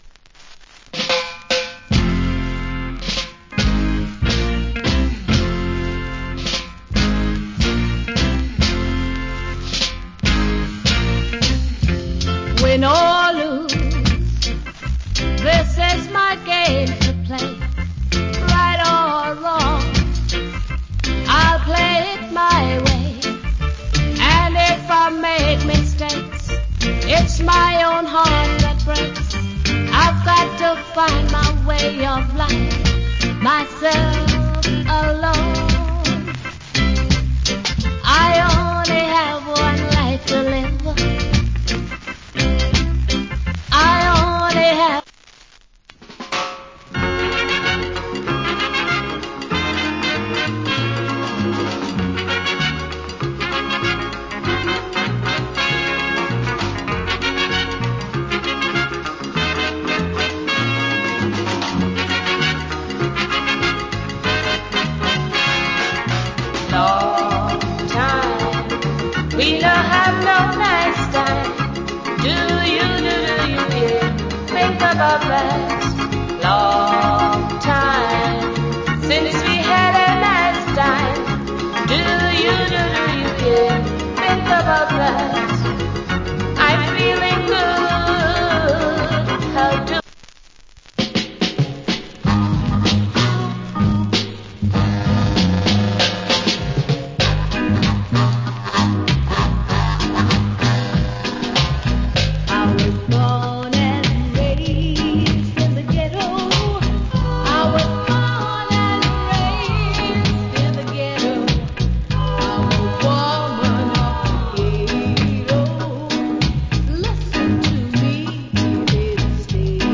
Great Female Reggae Vocal.